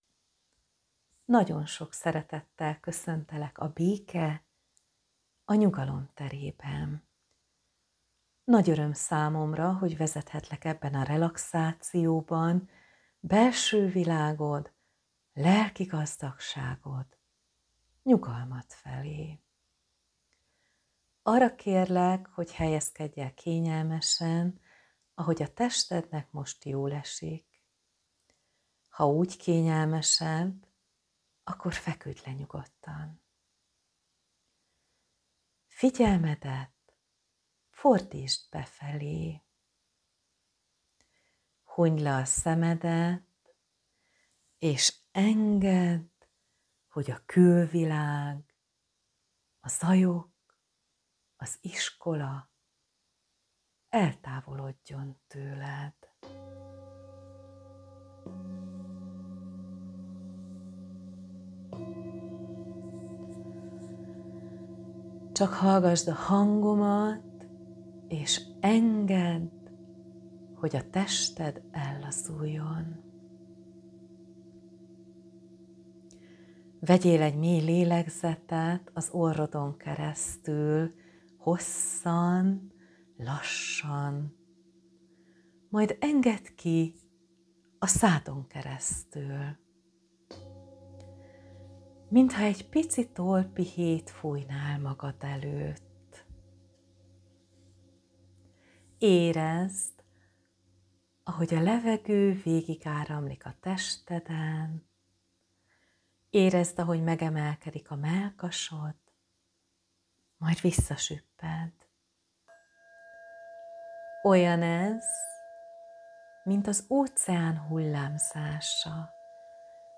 Hallgassátok meg az első, 15 perces relaxációt ingyen.